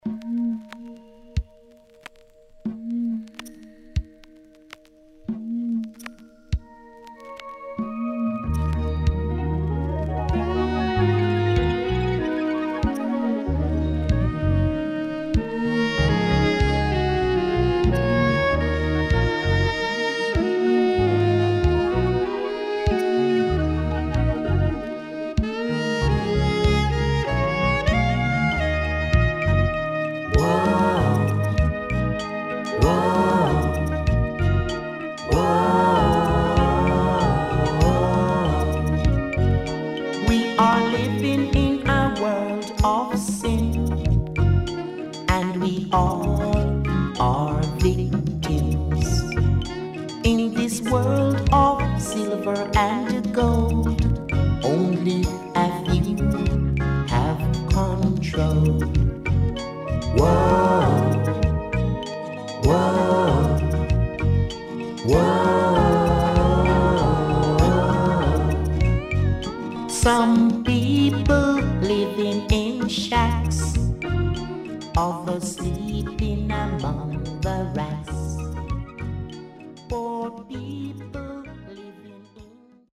93年K iller Stepper Roots
SIDE A:プレス起因でノイズ入ります。